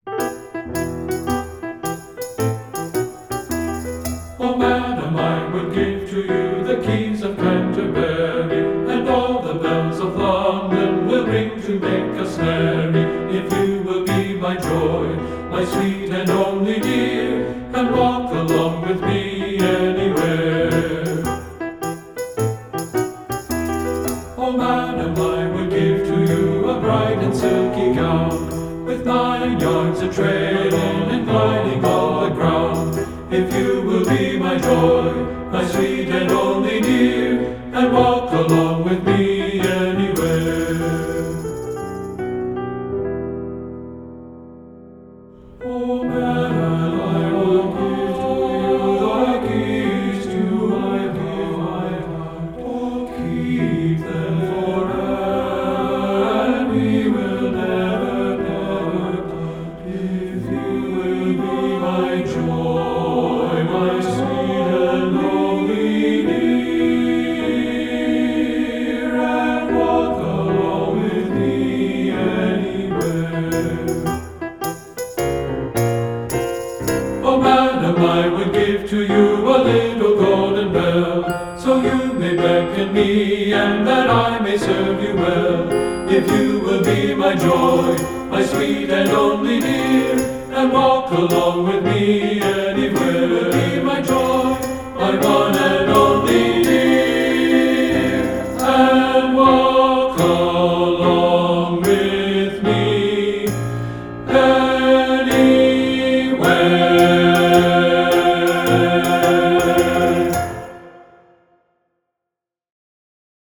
Voicing: TB Collection